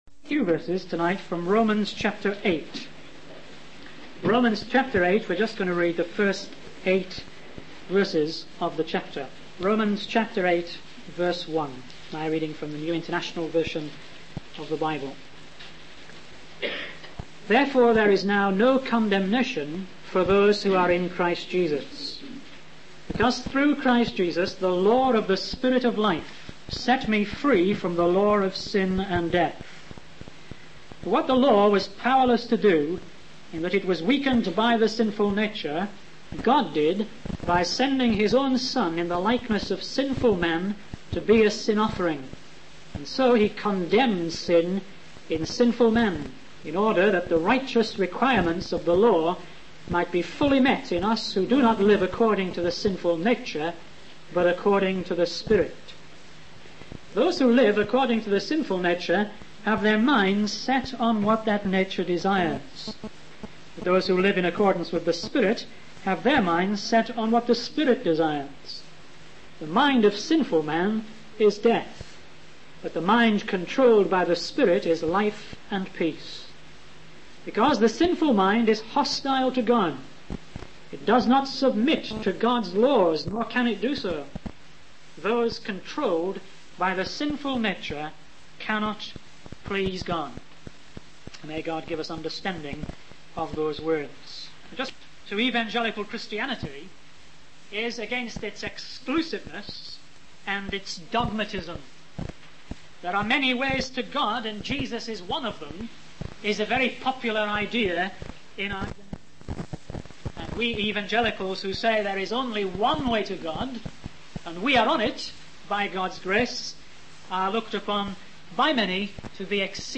In this sermon, the preacher tells a story about a young boy who fell into a deep pit and was unable to get out.